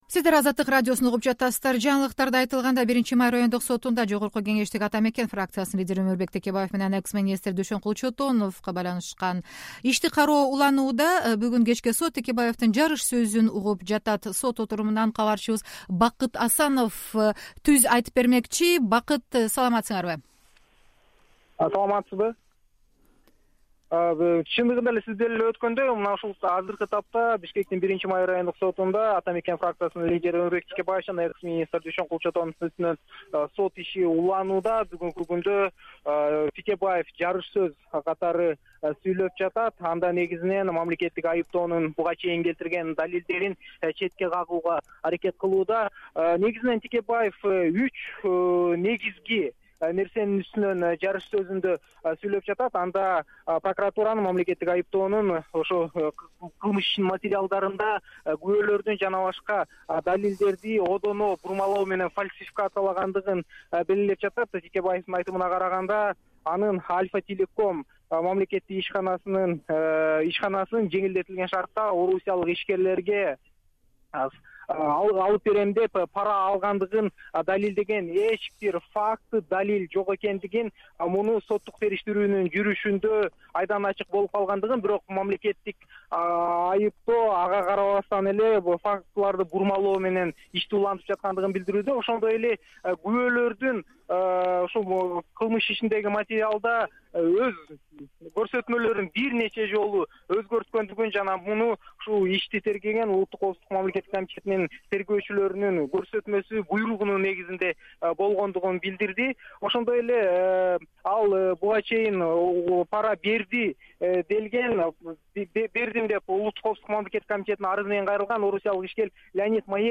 Текебаевдин сотунан репортаж (11.08.2017)